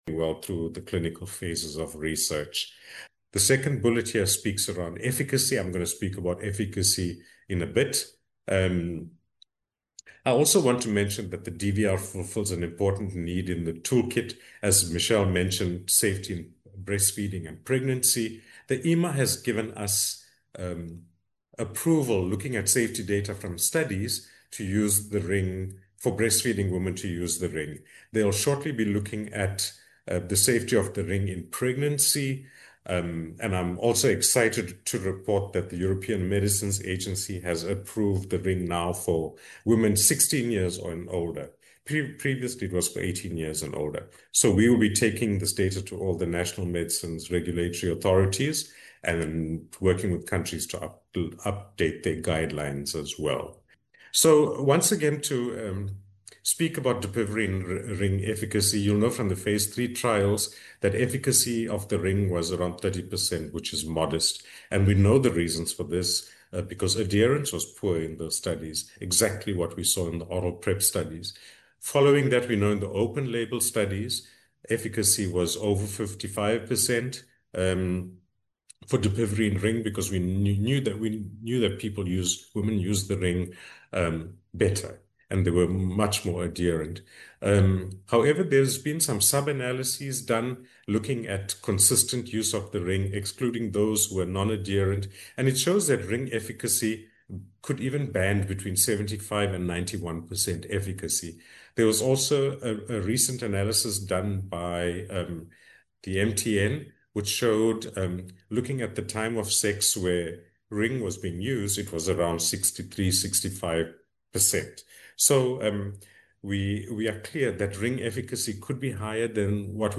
HIV-Prevention-Webinar-Three.mp3